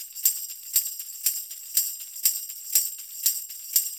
Pandereta_ ST 120_5.wav